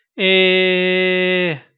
Vocale în format .wav - Vorbitorul #27